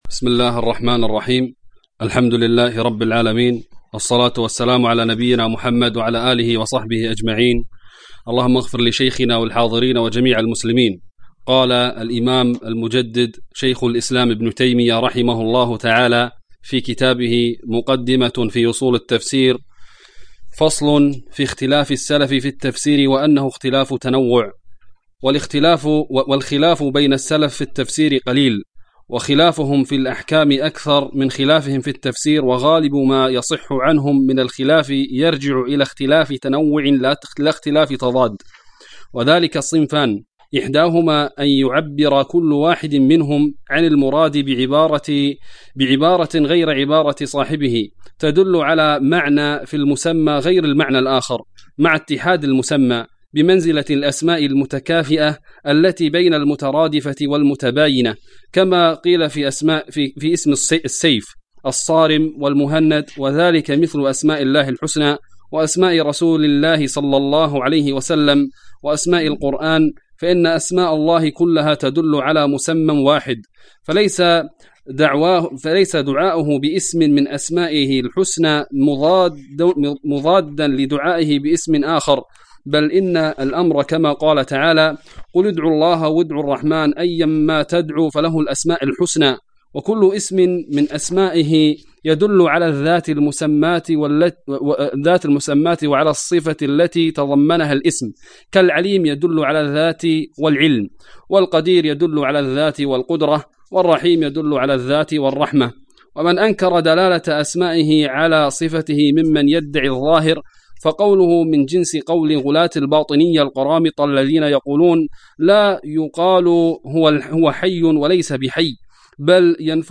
الدرس الثالث : فصل في اختلاف السلف في التفسير